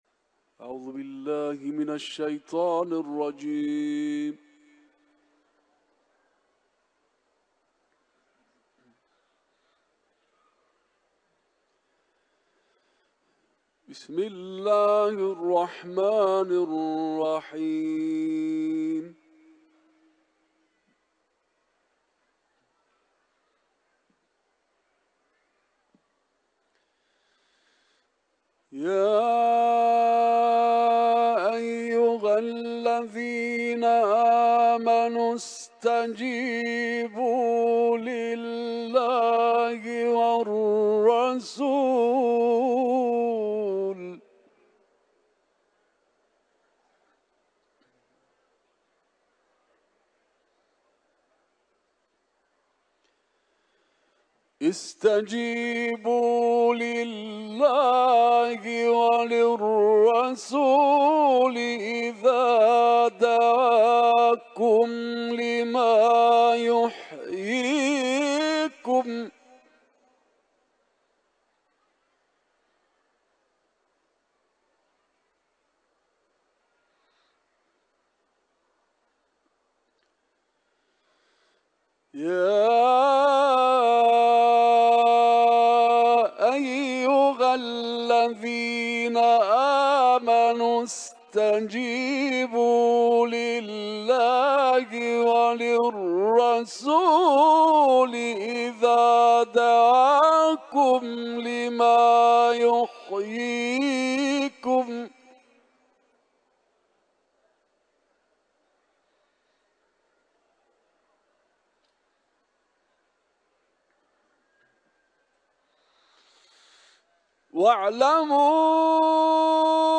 Kur’an-ı Kerim tilaveti